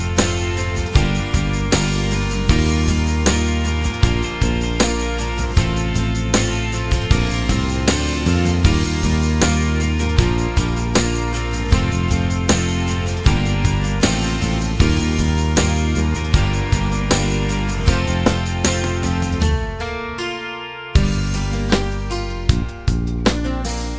no Backing Vocals Country (Male) 3:57 Buy £1.50